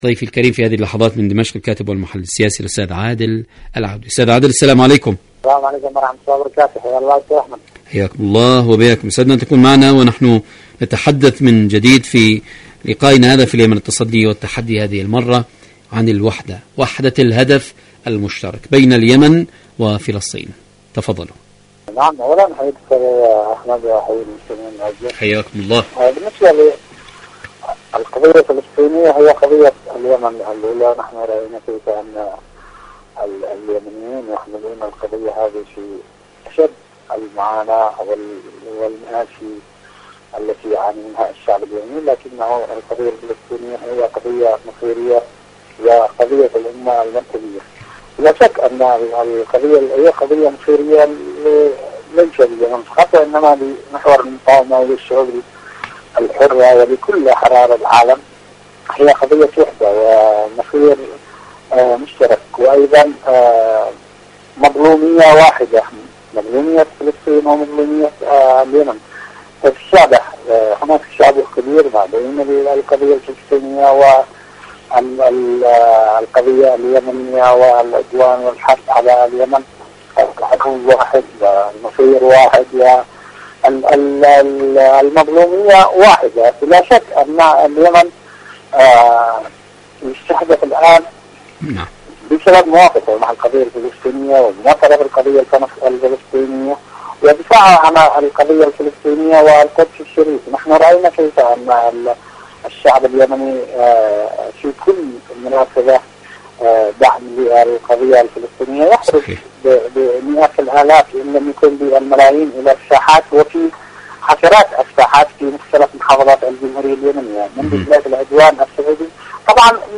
مقابلات برنامج اليمن التصدي والتحدي اليمن العدوان على اليمن مقابلات إذاعية برامج إذاعة طهران العربية الشعب اليمني حركة أنصار الله فلسطين وحدة الهدف شاركوا هذا الخبر مع أصدقائكم ذات صلة عاشوراء أيقونة الثوار..